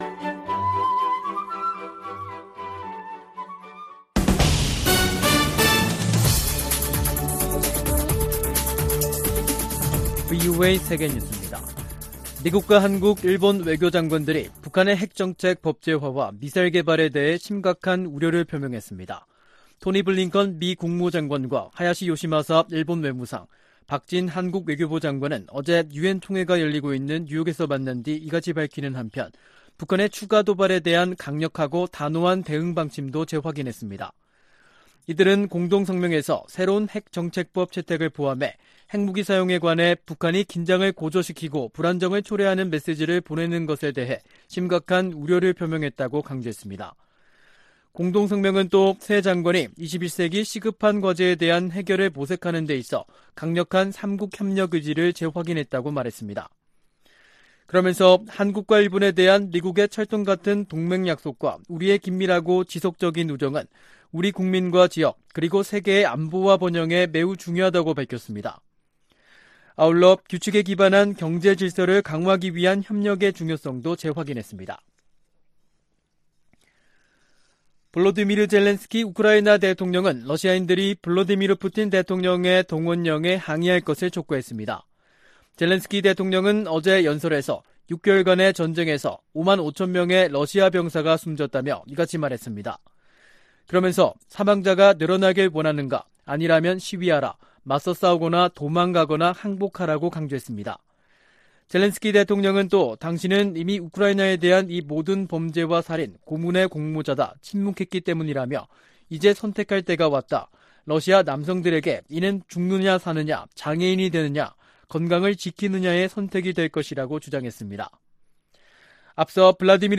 VOA 한국어 간판 뉴스 프로그램 '뉴스 투데이', 2022년 9월 23일 2부 방송입니다. 미 핵 추진 항공모함 로널드 레이건이 연합 훈련을 위해 부산에 입항했습니다. 미한일 외교장관들이 뉴욕에서 회담하고 북한의 핵 정책 법제화에 심각한 우려를 표시했습니다. 제이크 설리번 미국 국가안보보좌관은 북한의 7차 핵 실험 가능성이 여전하다고 밝혔습니다.